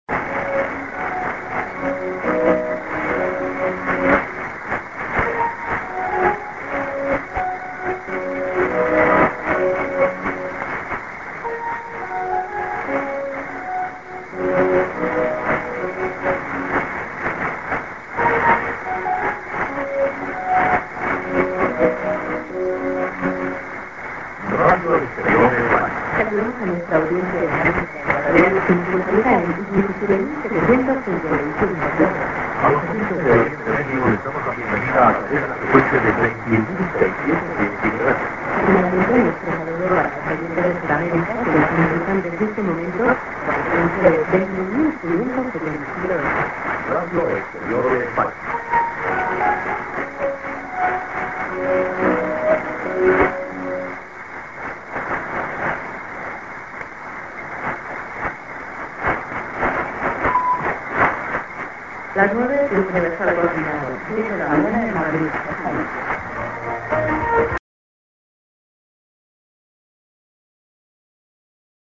a:　IS->ID+SKJ(man+women)->+IS->TS->